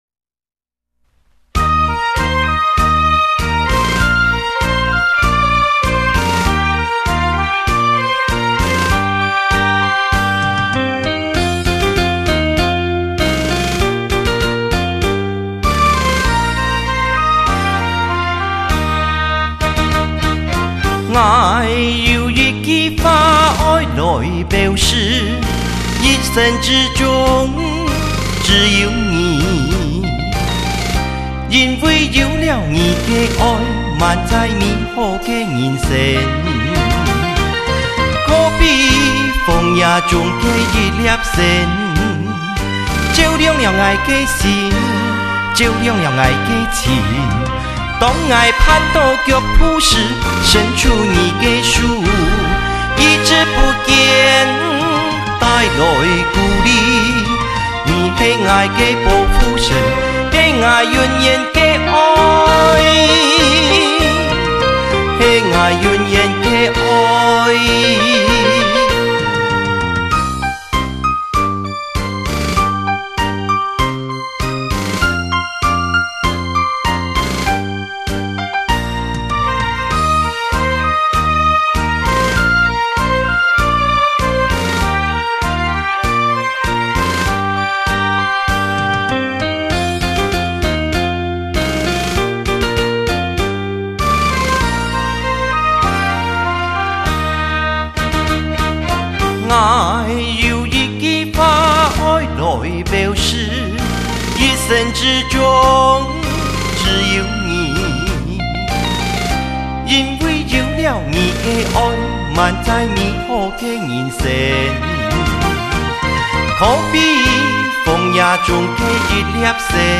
听了一下，感觉上与国语歌曲的演唱 嗓音反差很大，应该是早期的专辑吧！~